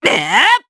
Valance-Vox_Attack3_jp.wav